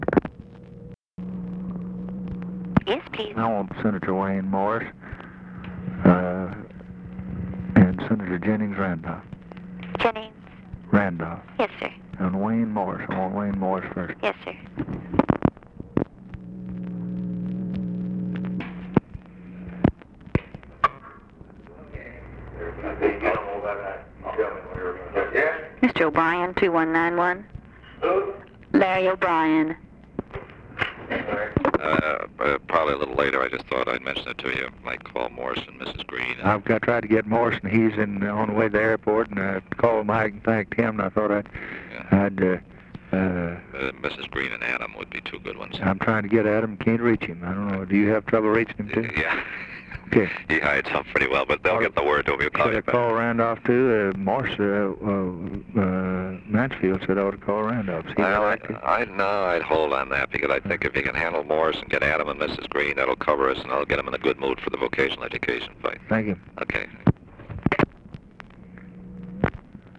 Conversation with LARRY O'BRIEN, December 10, 1963
Secret White House Tapes